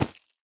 Minecraft Version Minecraft Version snapshot Latest Release | Latest Snapshot snapshot / assets / minecraft / sounds / block / cake / add_candle1.ogg Compare With Compare With Latest Release | Latest Snapshot
add_candle1.ogg